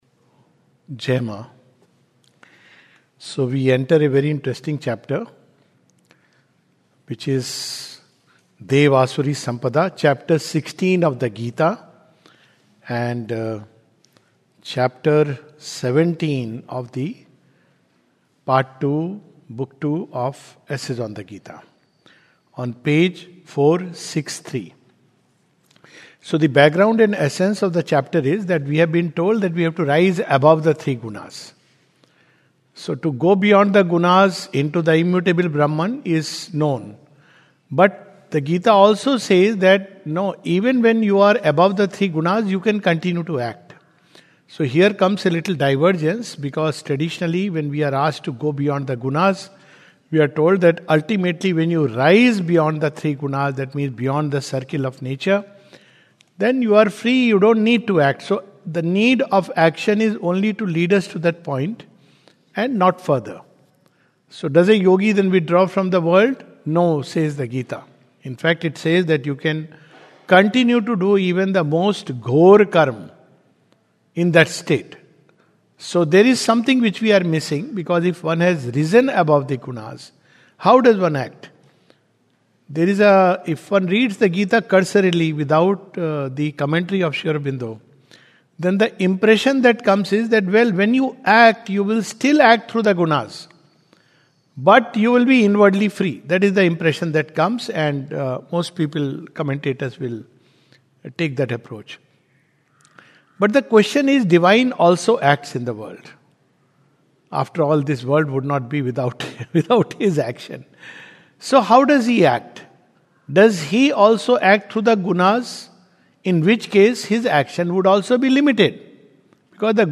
This is a summary of Chapter 17 of the Second Series of "Essays on the Gita" by Sri Aurobindo. A talk
2025 at the Savitri Bhavan, Auroville.